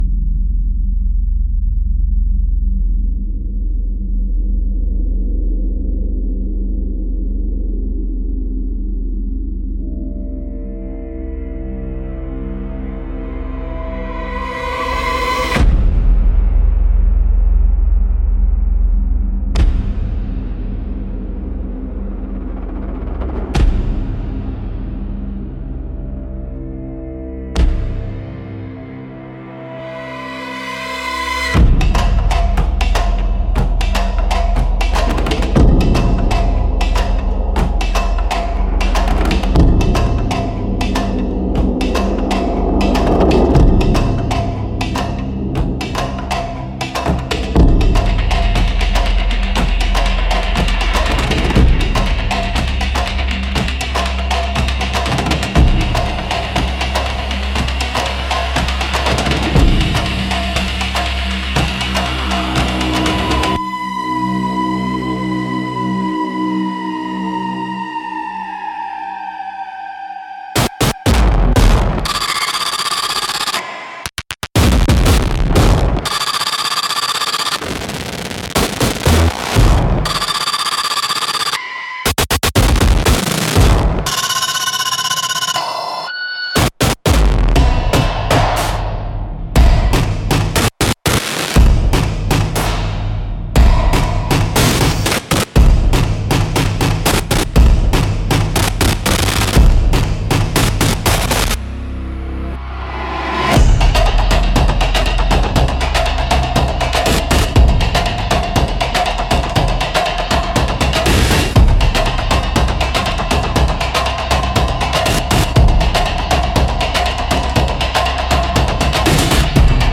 Instrumental - Zero Point Dreaming - 3.13